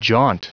Prononciation du mot jaunt en anglais (fichier audio)
Prononciation du mot : jaunt